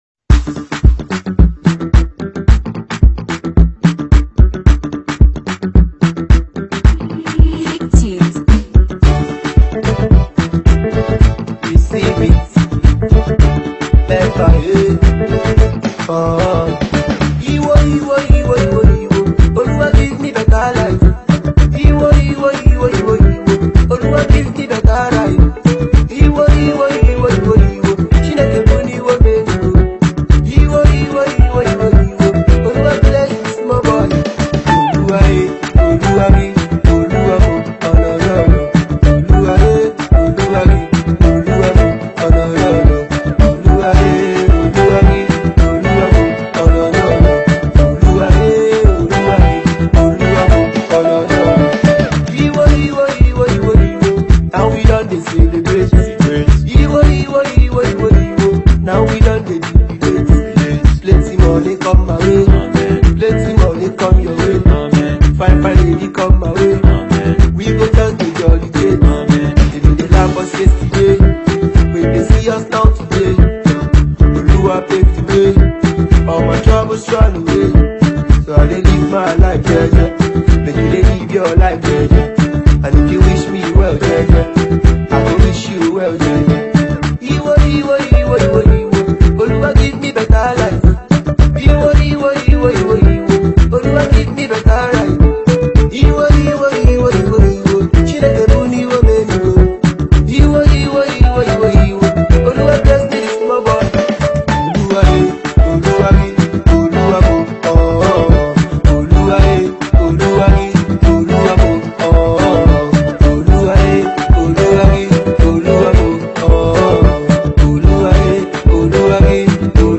Sensational singer
high-life number
igbo indigenous rapper